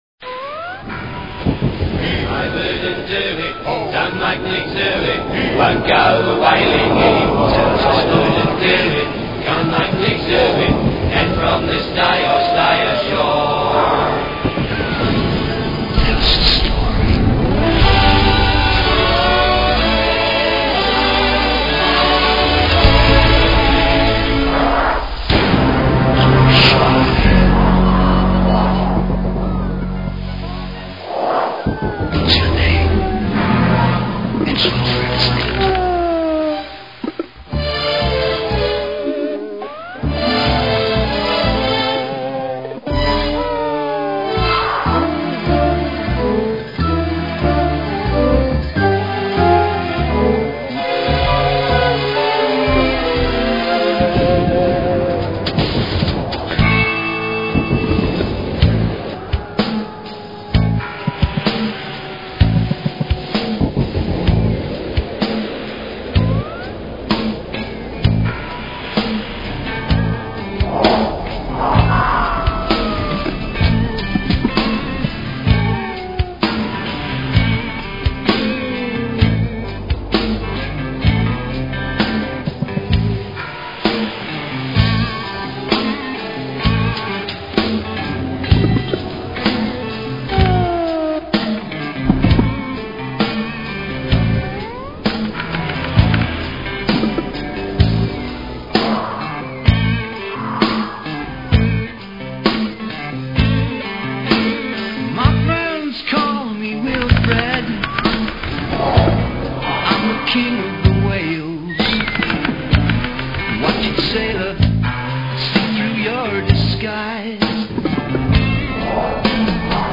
mpeg - wilfred.wav (mono mpeg download1,166k)
I had played him the first demo that I ever did of the song "Wilfred Whale" (which he thought would be a commercial hit if properly recorded) and told him that I was keen to put any money that I made from commercial music into antigravity research (I was a real UFO nut).